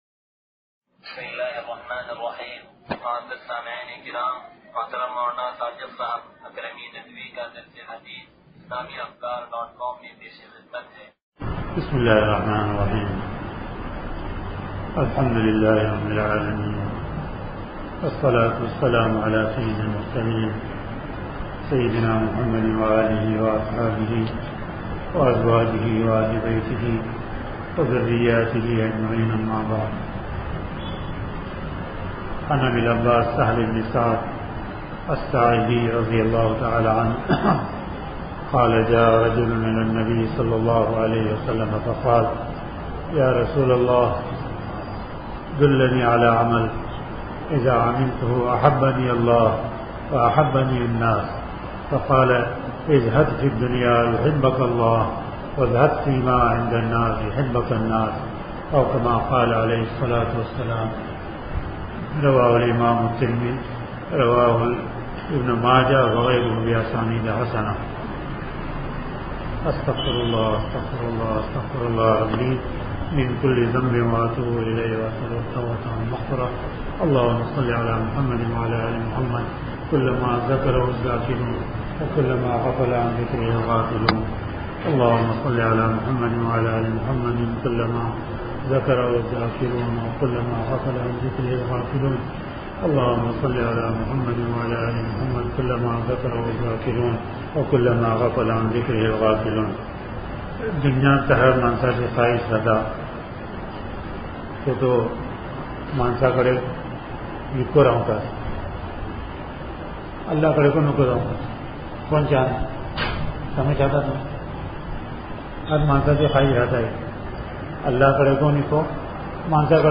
درس حدیث نمبر 0500